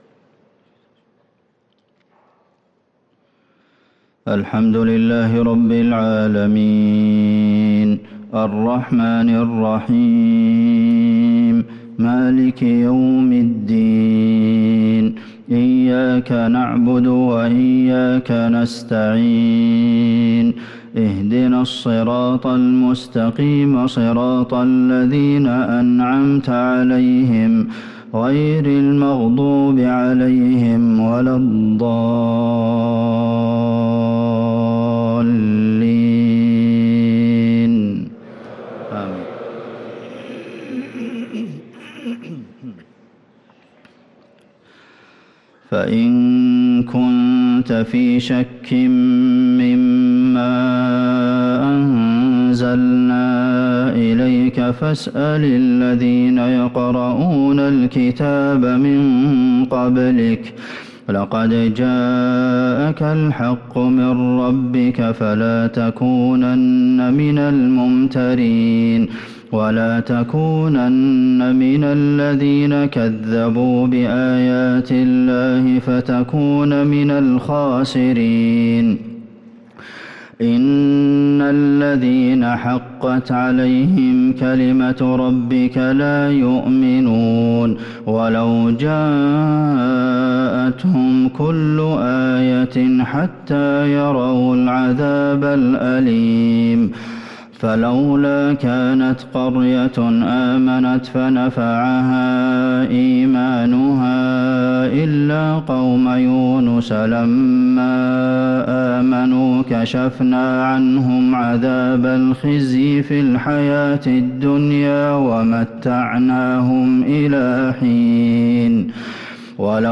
صلاة الفجر للقارئ عبدالمحسن القاسم 8 ربيع الأول 1445 هـ
تِلَاوَات الْحَرَمَيْن .